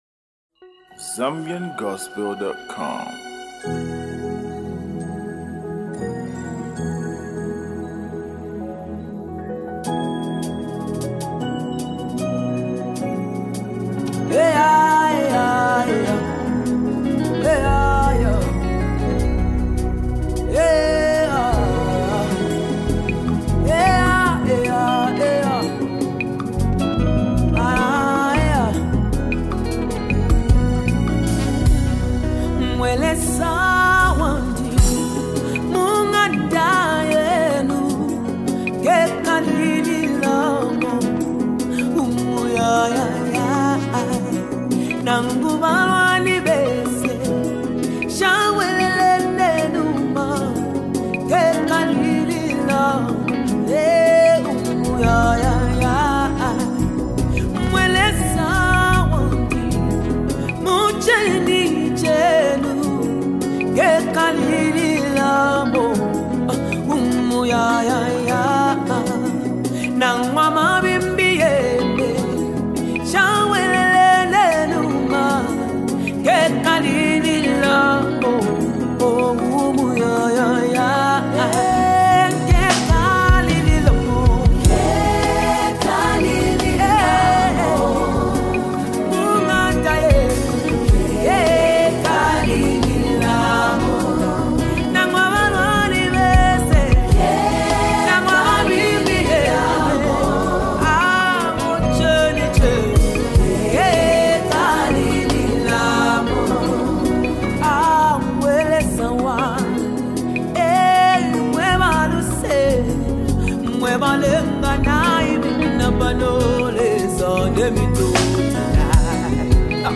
Gospel Music
With its uplifting melody and powerful lyrics
create an atmosphere of worship and reflection